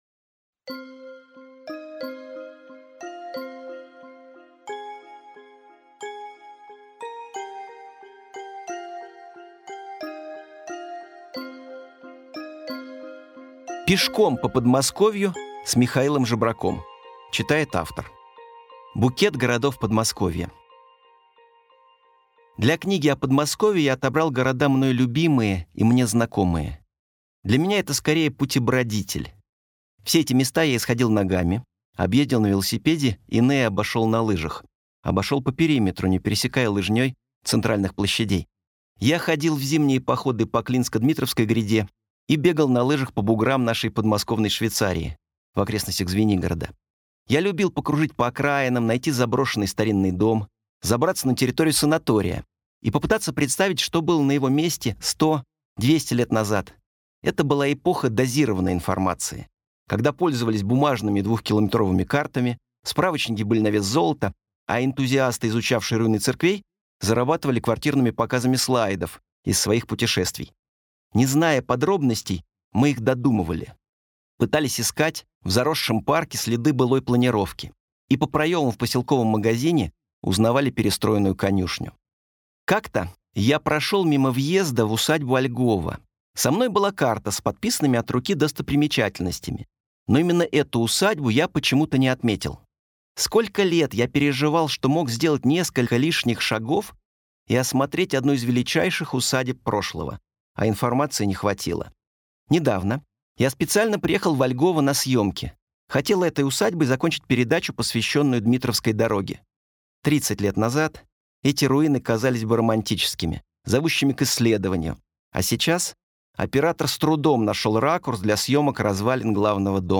Аудиокнига Пешком по Подмосковью | Библиотека аудиокниг